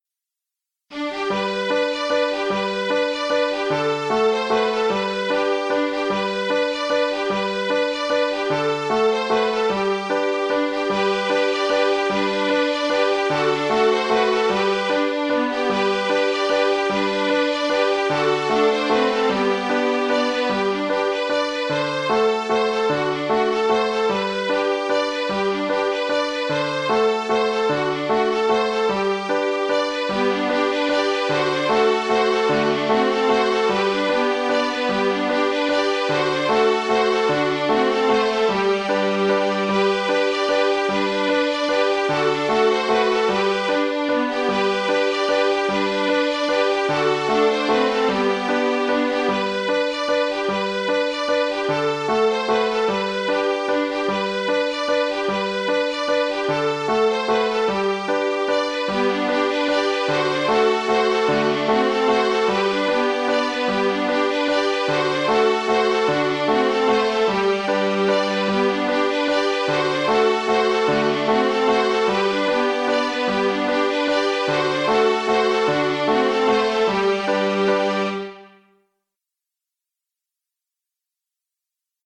Mazurka de Lapleau (Mazurka) - Musique folk
Le contrechant est aussi très simple..
Mazurka